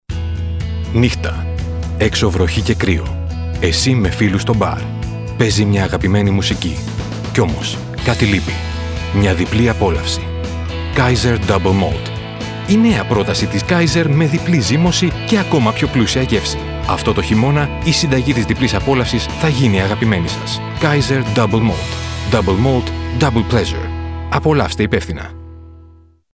greek and english speaker,voice over talent,sound engineer,musician,music supervisor
Kein Dialekt
Sprechprobe: Werbung (Muttersprache):